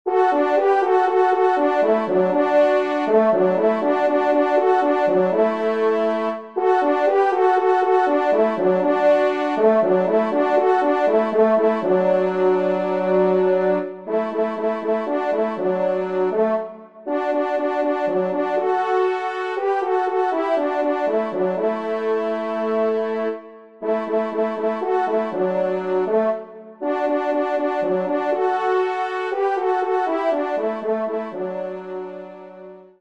1e Trompe           2e Trompe